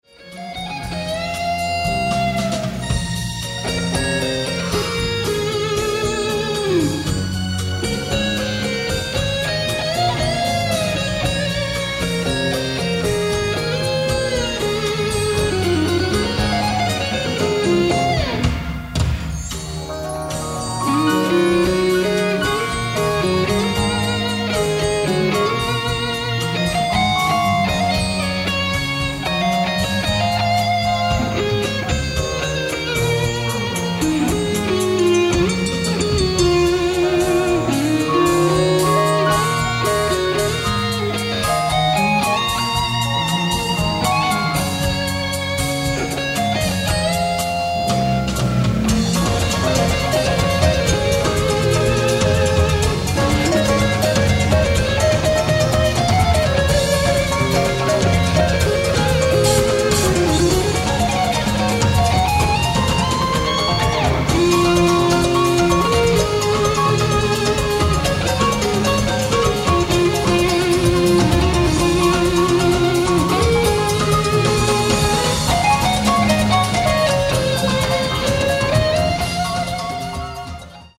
ライブ・アット・シビック・オーディトリアム、サンタモニカ、カリフォルニア 07/28/1978
※試聴用に実際より音質を落としています。